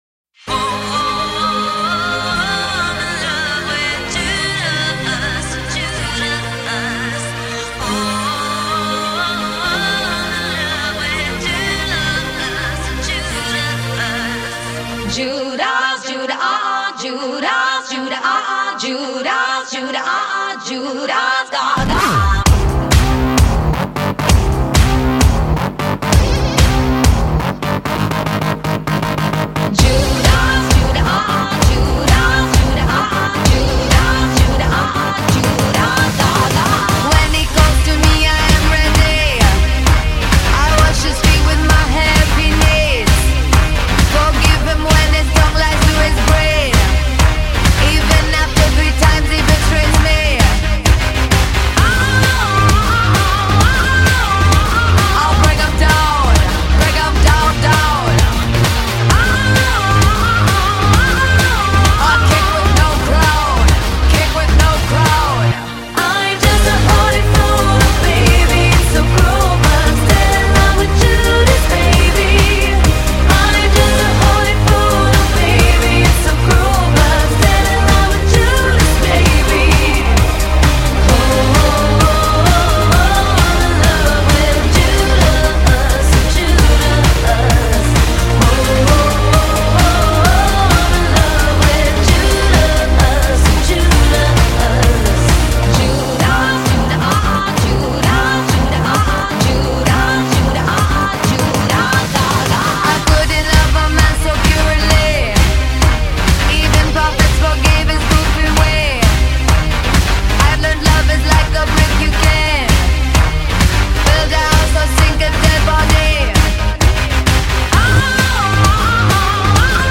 Pop [52]